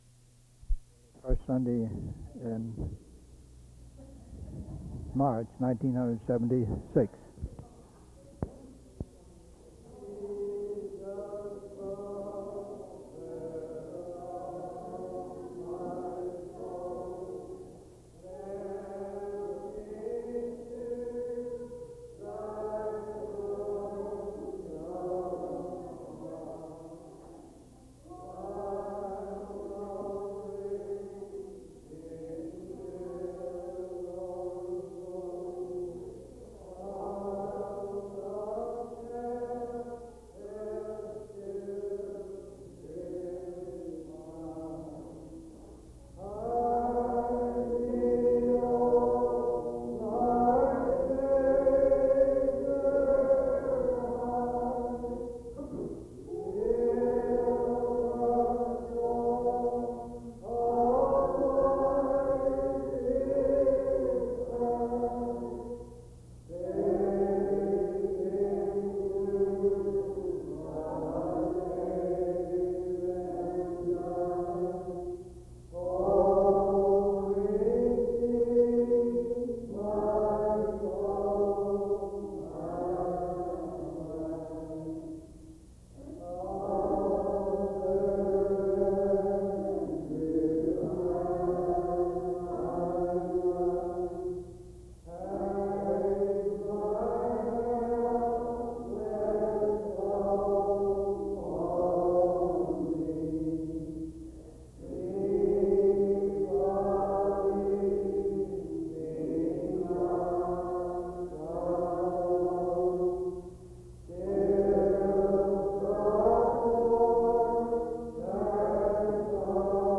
Sermon without a focus text, covering the inspiration of scripture by God and the Spirit speaking to the elect